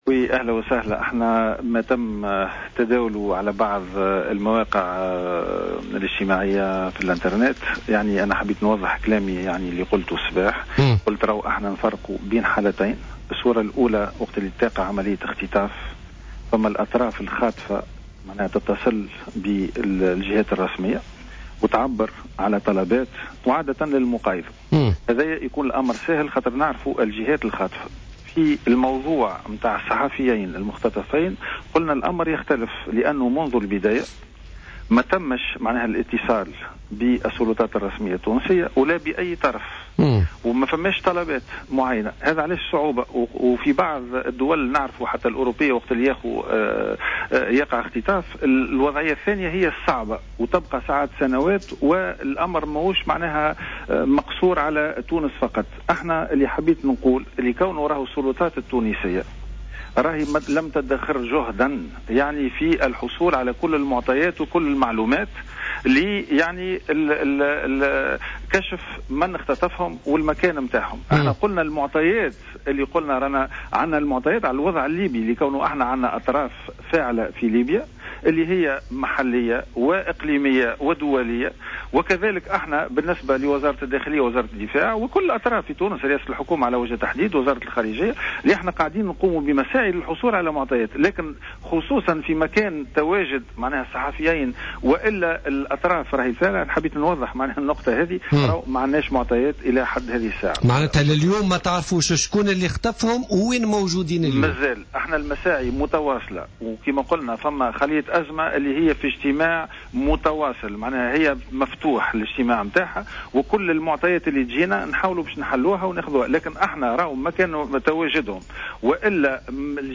نفى وزير الدفاع غازي الجريبي في تدخل له على جوهرة أف أم اليوم خلال حصة بوليتيكا...